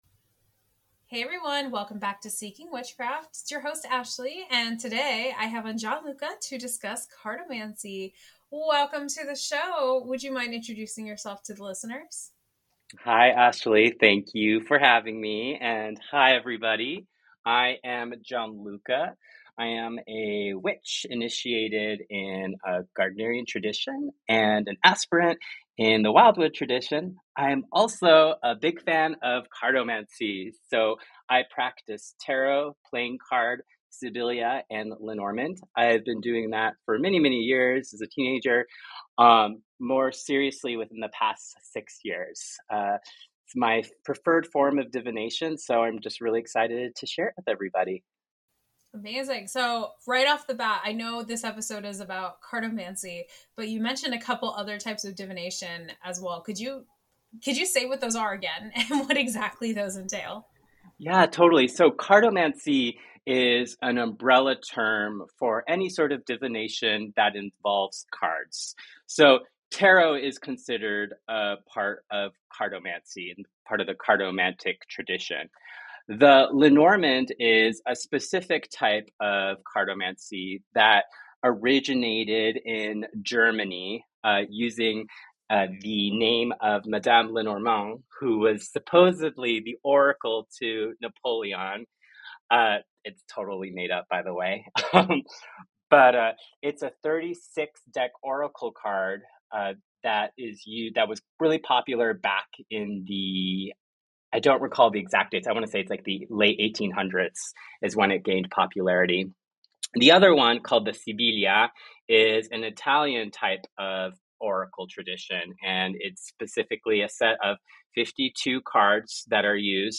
Whether you're a curious beginner or a seasoned practitioner, this conversation promises to enlighten and inspire.